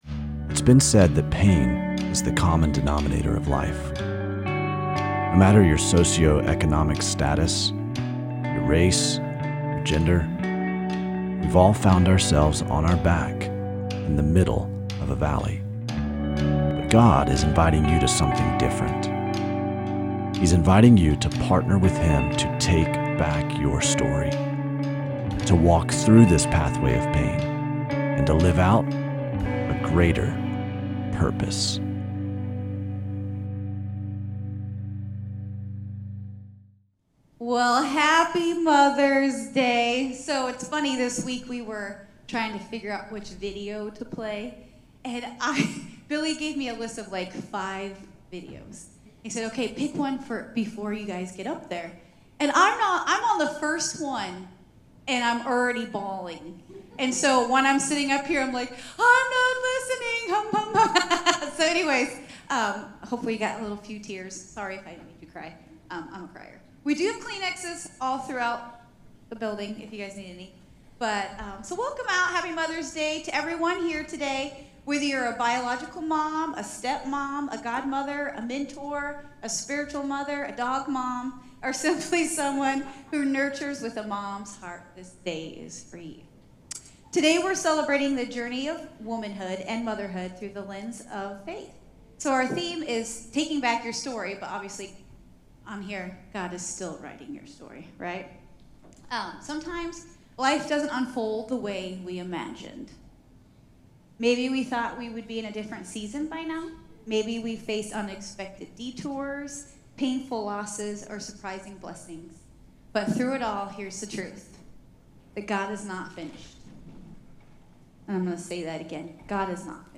Mother's Day panel of women.